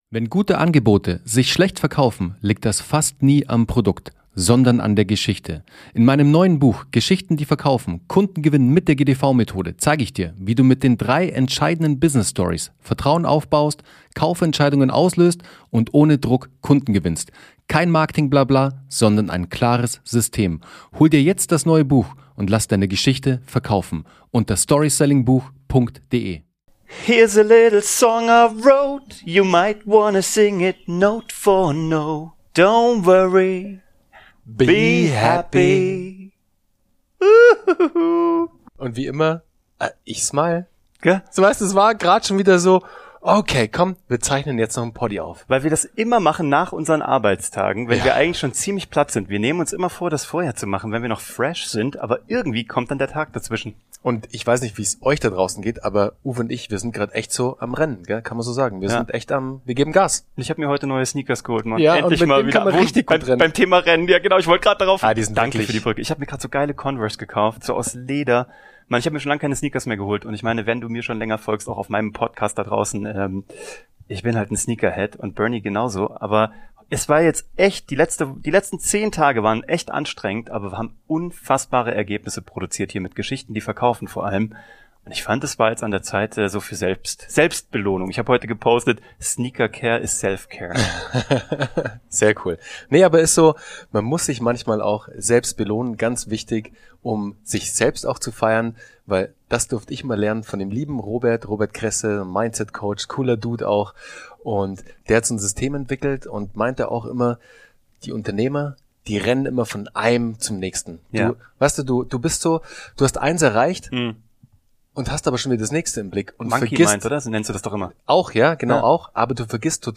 Diese Folge ist voller Energie.